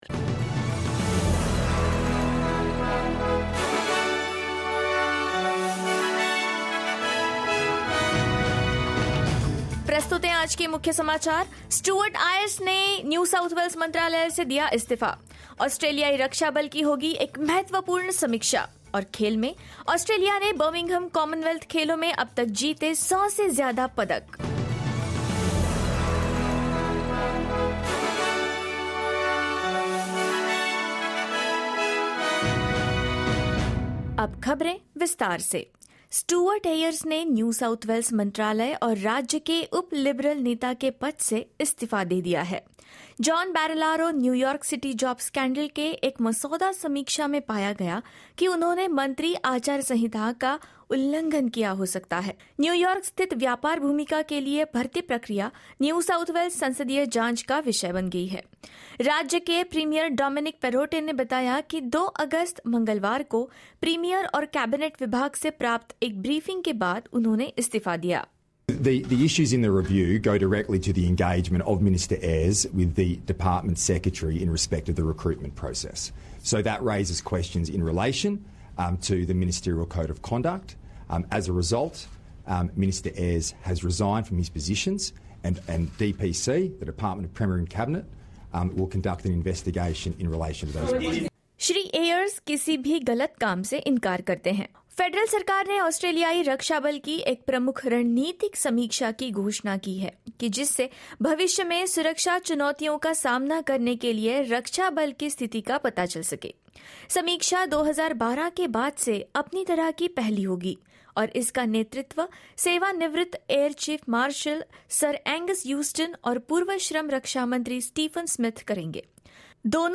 In this SBS Hindi news bulletin: Stuart Ayres resigns as NSW Trade Minister over John Barilaro US job scandal; Prime Minister Anthony Albanese announces a major strategic review of the defence force; Australia secures more than 100 medals at the Commonwealth Games in Birmingham and more.